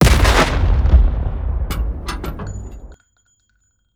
PNCannonSound.wav